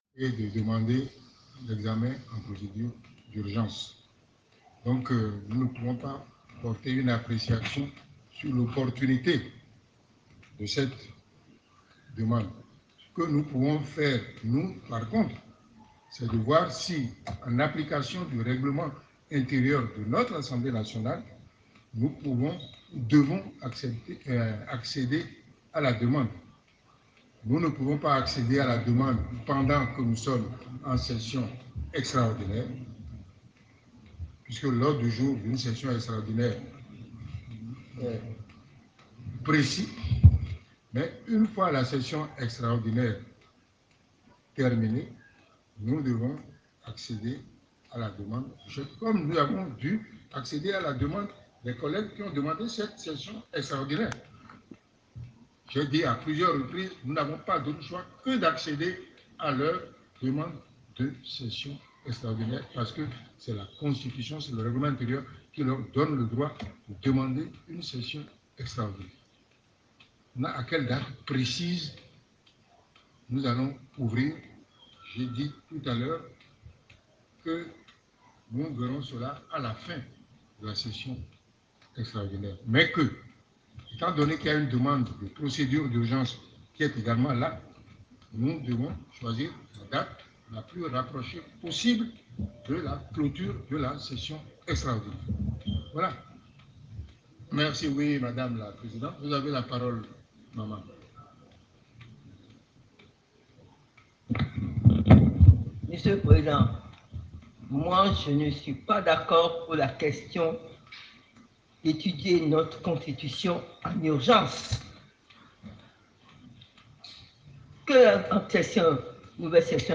Première réaction depuis le Palais des Gouverneurs :